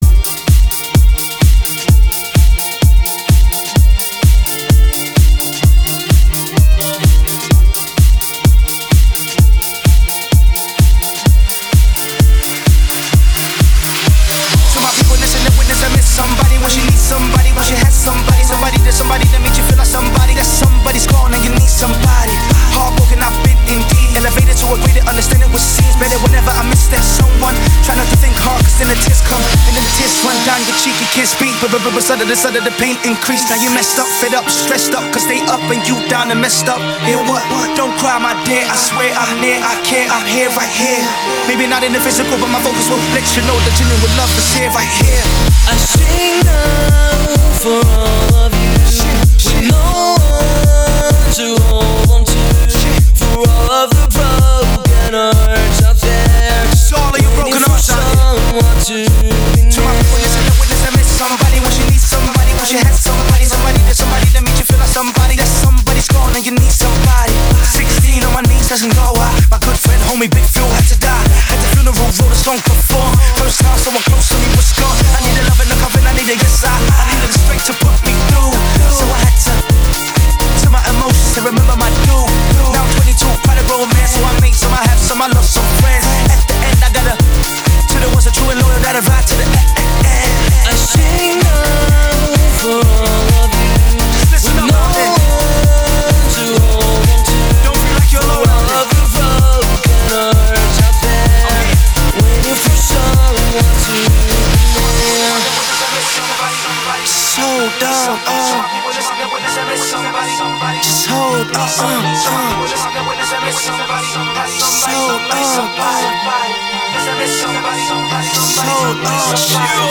Жанр: House - Electro